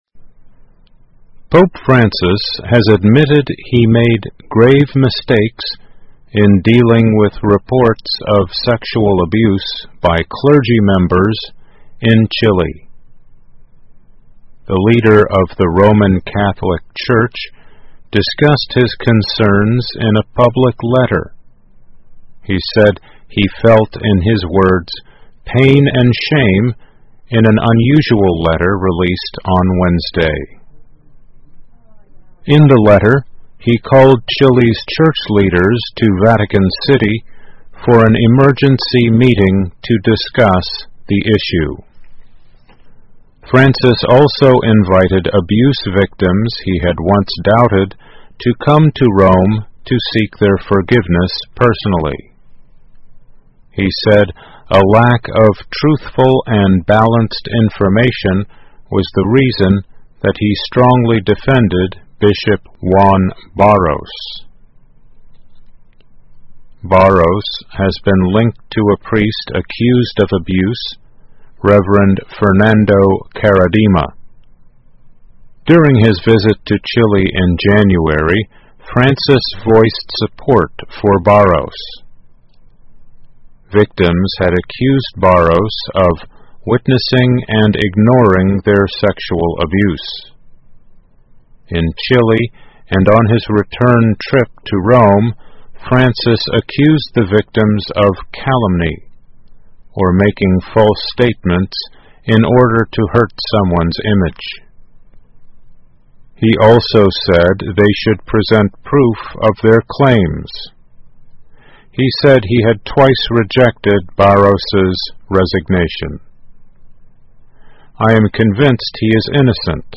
VOA慢速英语2018 教宗方济各承认处理智利性虐丑闻时犯了“严重错误” 听力文件下载—在线英语听力室